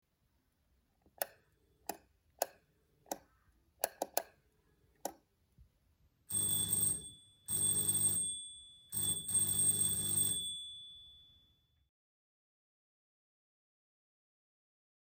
Звуки звонка в дверь